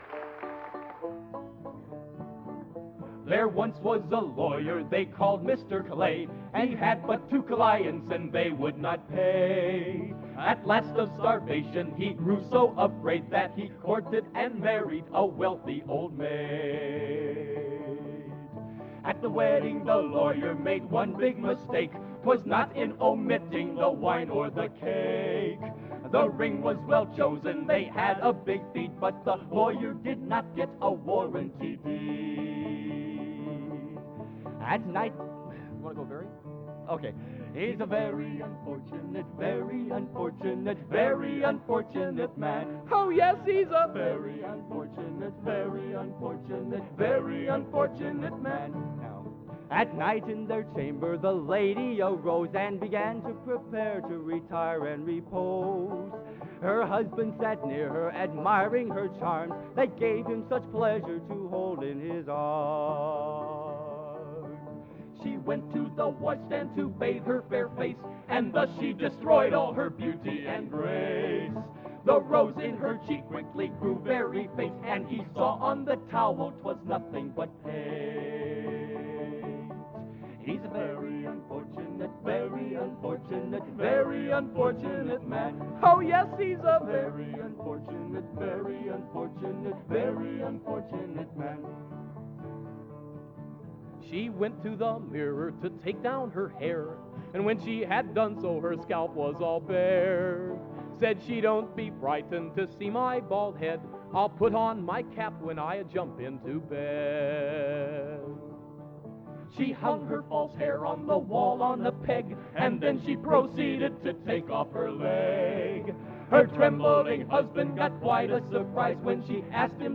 Genre: | Type: End of Season |Featuring Hall of Famer